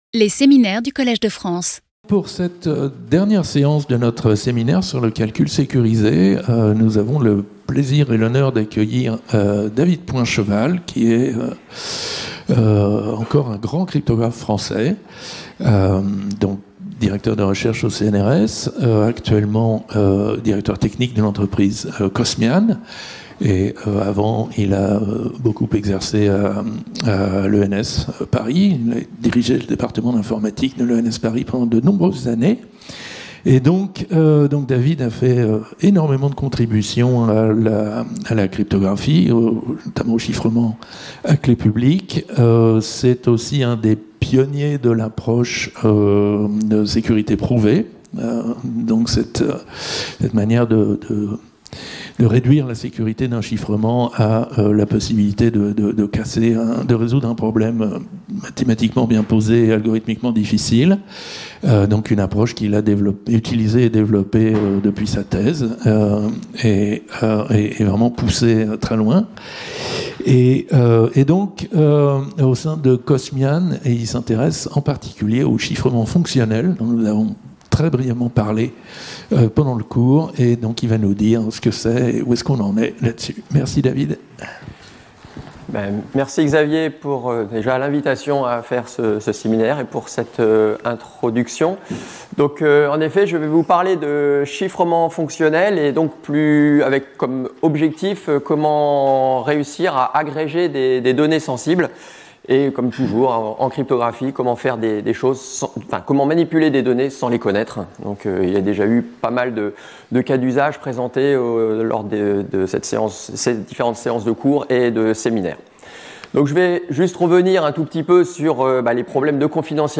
In this talk, we will first present the origin of this primitive, with "single-user" functional encryption, where the data of a single entity is encrypted.